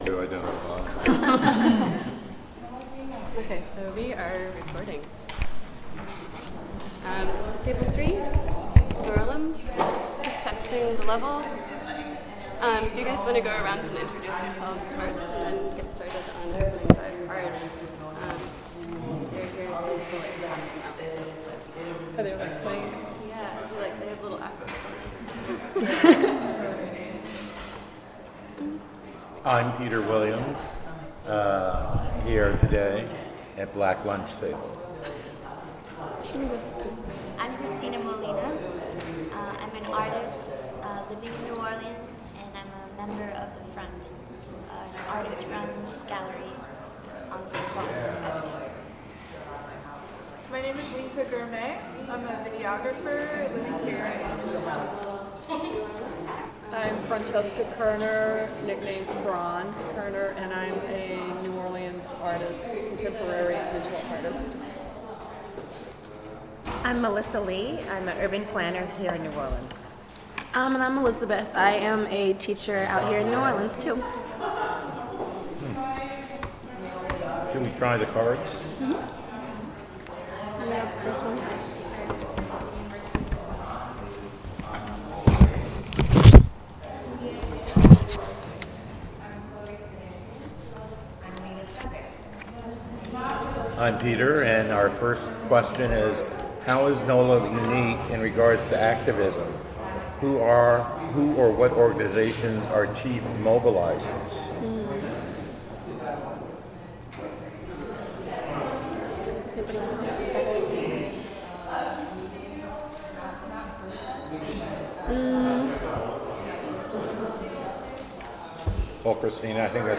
sound recording-nonmusical
oral history